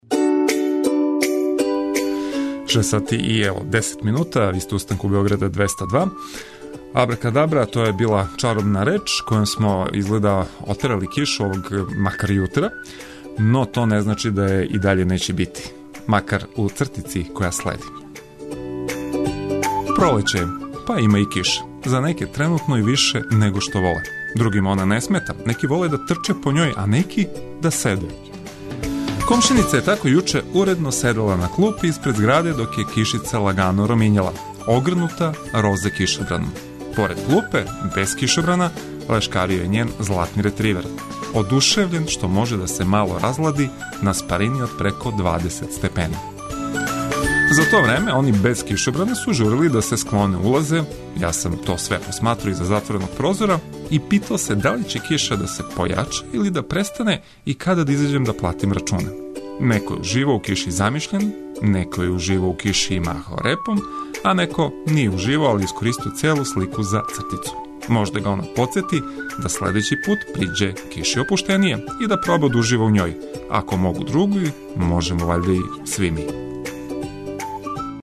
Да не бисмо рекли или урадили нешто због чега ће нас терати малер, овог јутра мање причамо а више слушамо музику за разбуђивање.